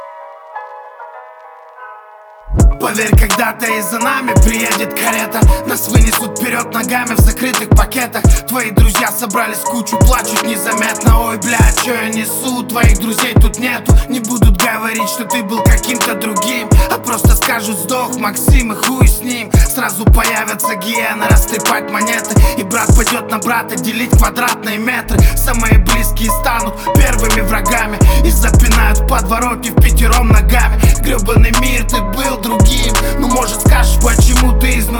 Жанр: Рэп и хип-хоп / Иностранный рэп и хип-хоп / Русские
# Hip-Hop